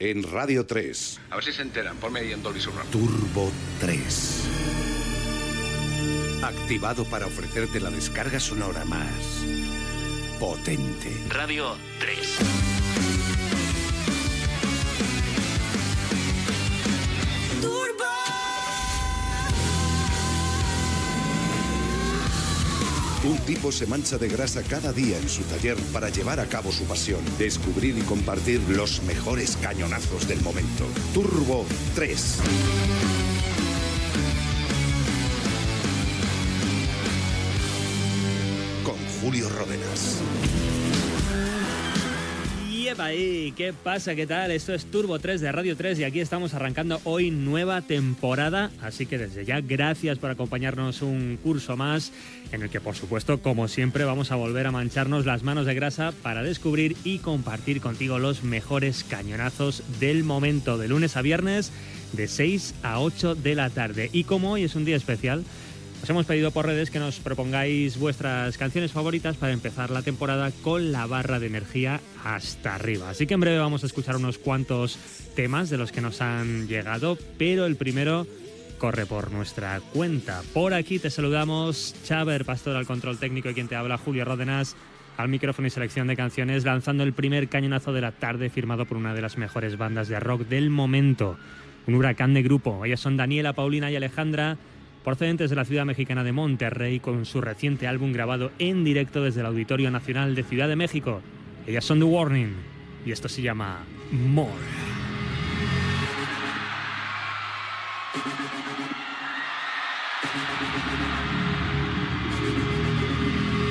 Careta del programa, presentació de la primera edició de la temporada 2025-2026 i tema musical.
Musical
FM